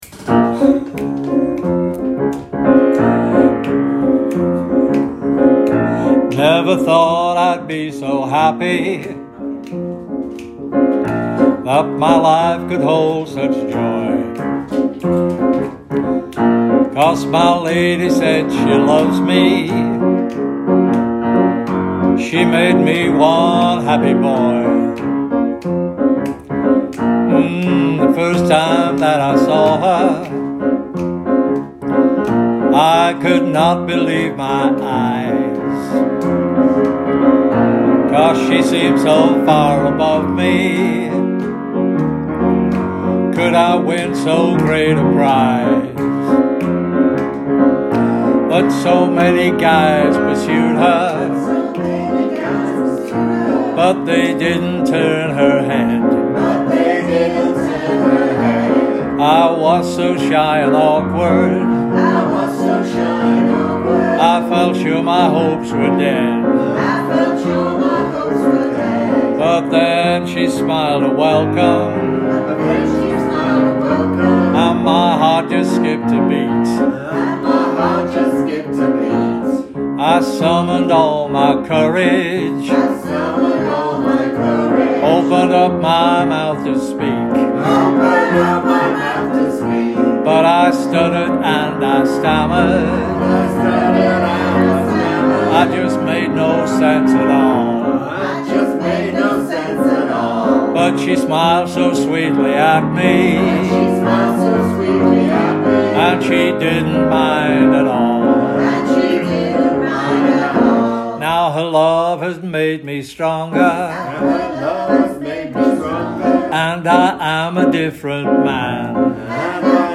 An original song